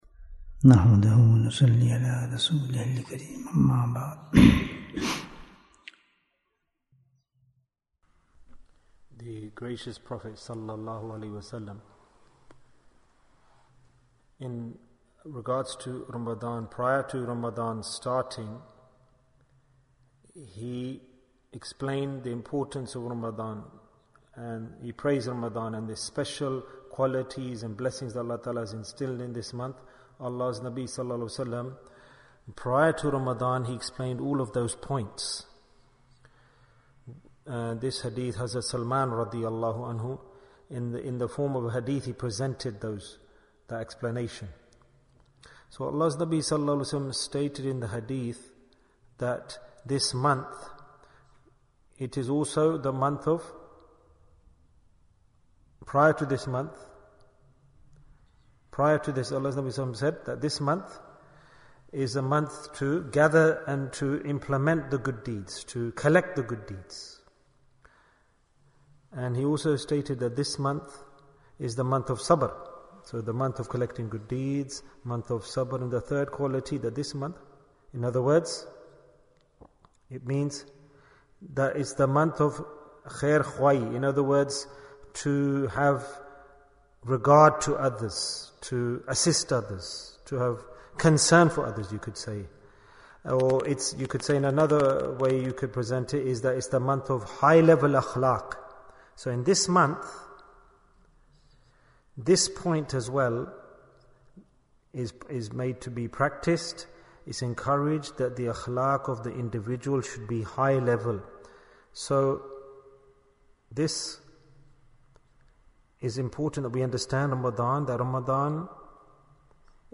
The Most Rewarding Deed in Ramadhan Bayan, 43 minutes29th March, 2023